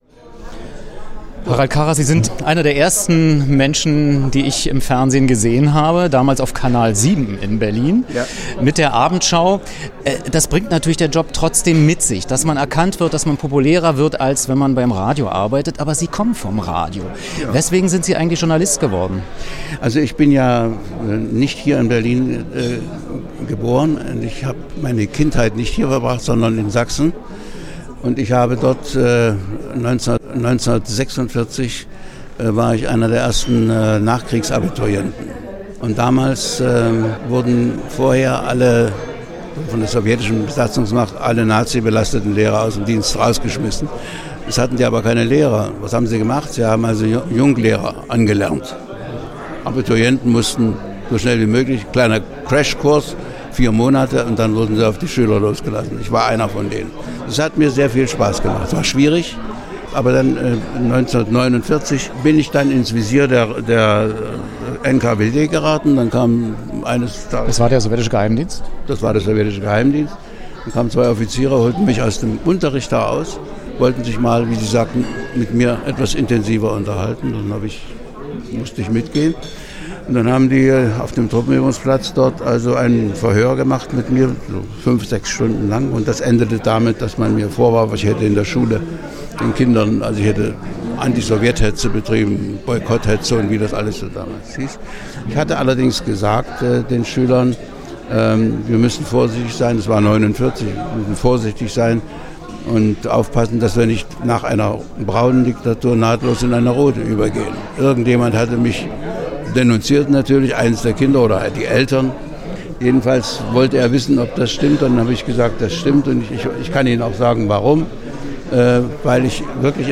Interview zum journalistischen Werdegang und den Anfängen der Abendschau
Berlin, Zollpackhof